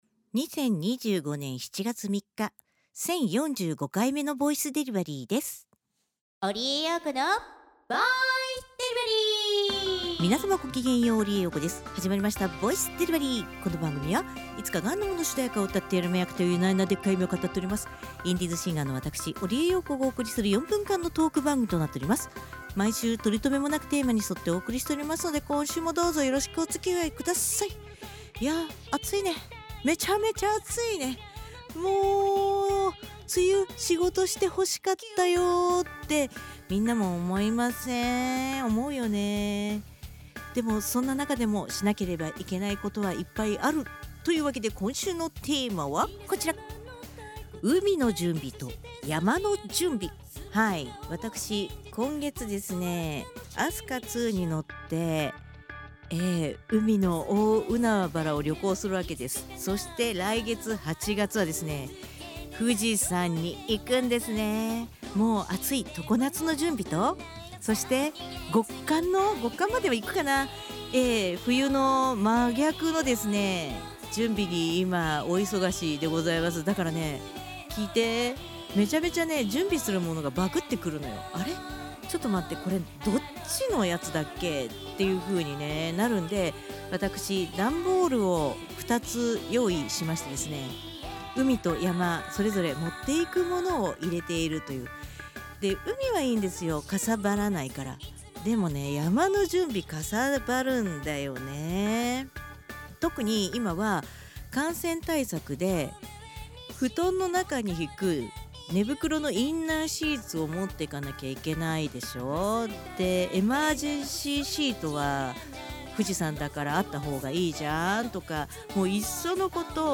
毎週水曜日か木曜日更新の４分間のトーク番組（通称：ぼいでり）時々日記とTwitterアーカイブ